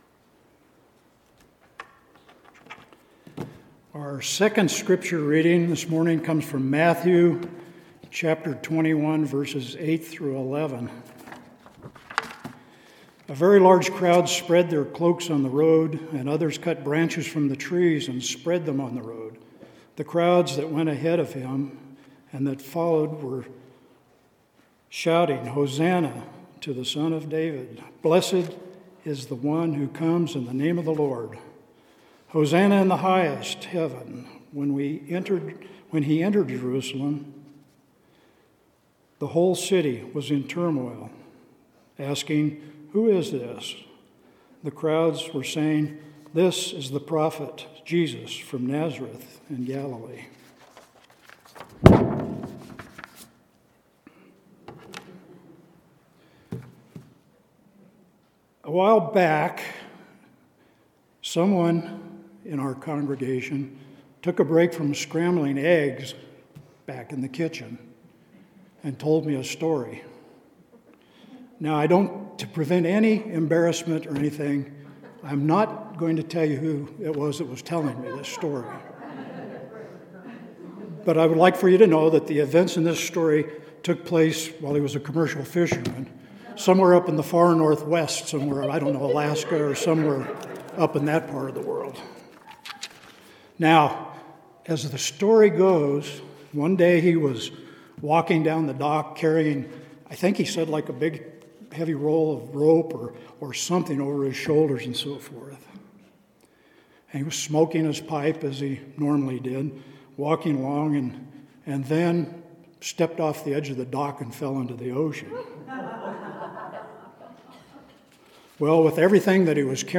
Sermon – April 13th – “How Will We Respond?”